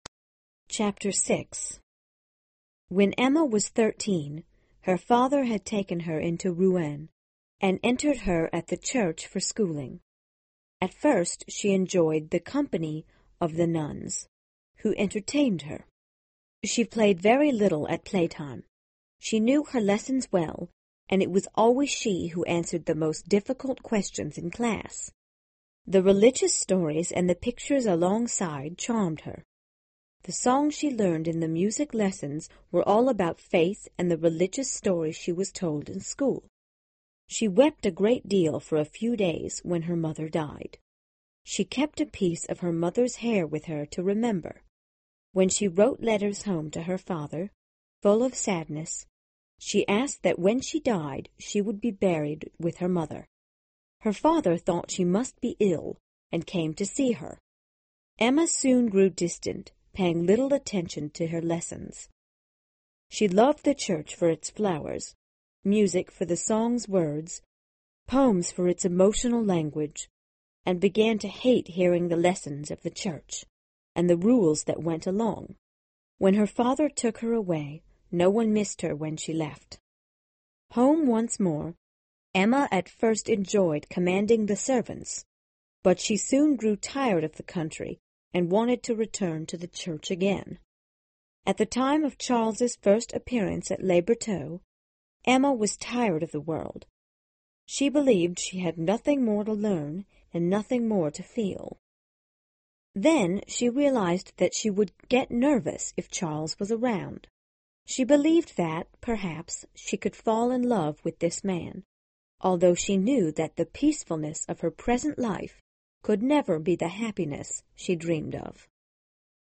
有声名著之包法利夫人 06 听力文件下载—在线英语听力室